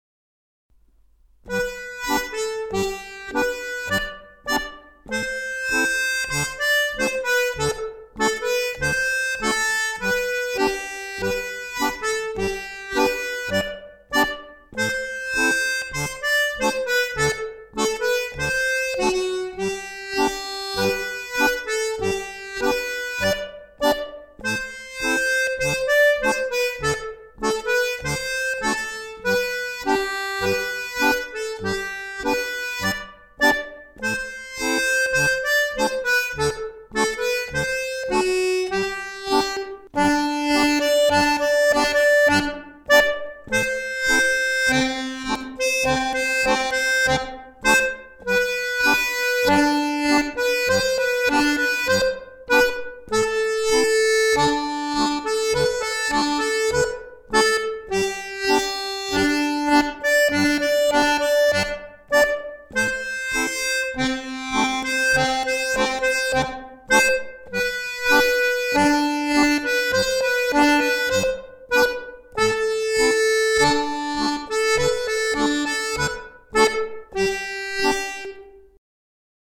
Tabs for chromatic accordion
La semaine des 7 lundis* Scottish à 2 voix Voix 1
Voix 1 Lent